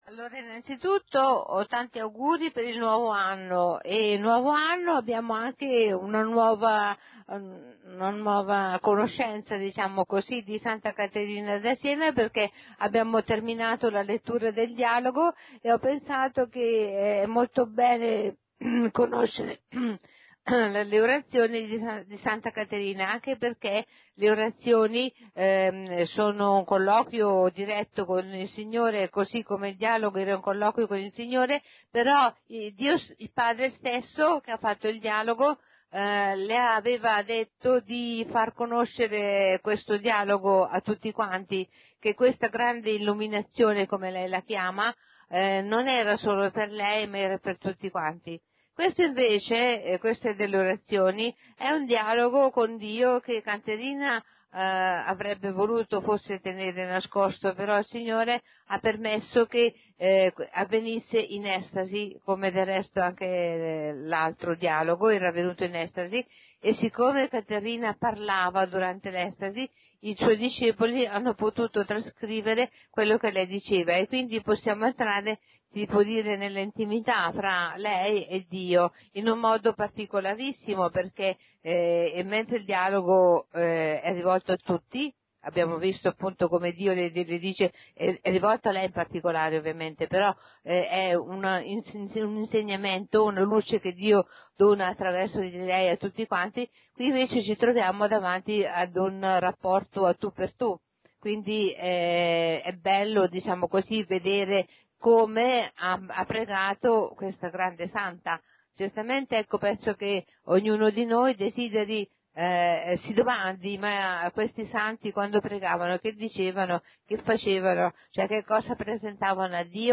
Lettura delle Orazioni e commento di S. Caterina